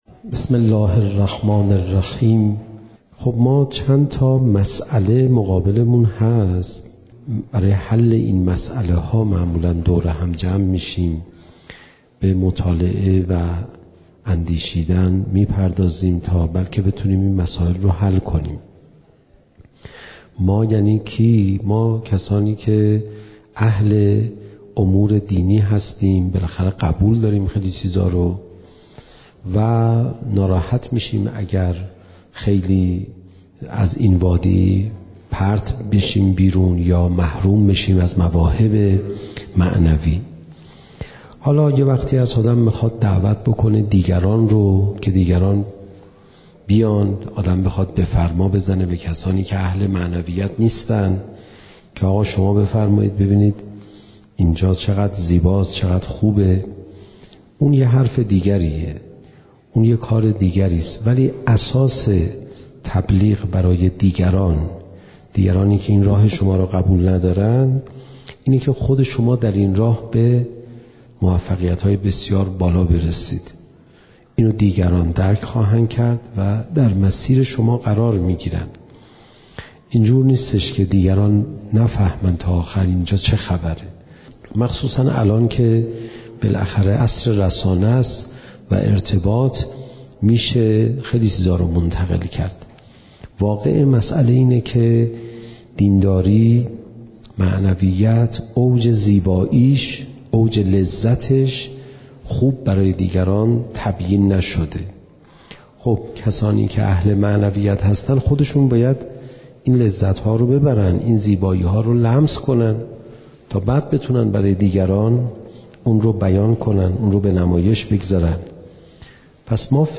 زمان: 01:01:46 | حجم: 14.4 MB | مکان: آستان مقدس حضرت صالح (علیه السلام) - تهران | تاریخ: رمضان 1401ش